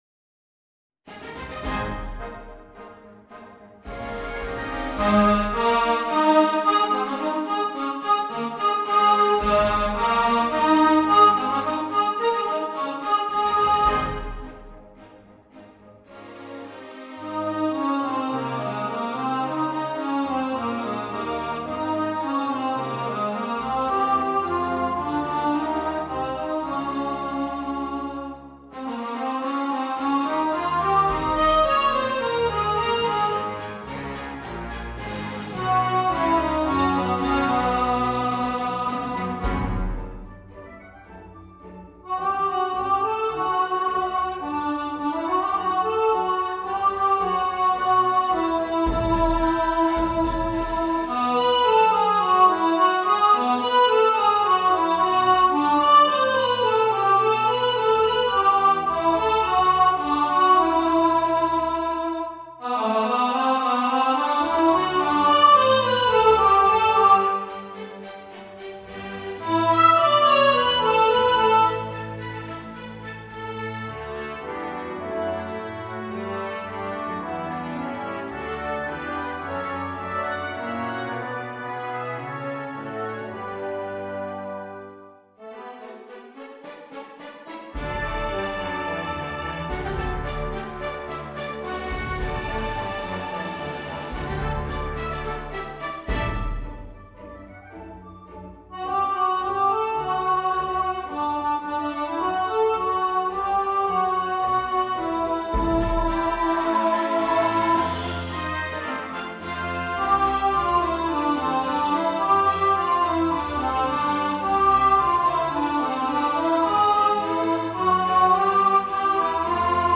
Spirit Of The Season Alto | Ipswich Hospital Community Choir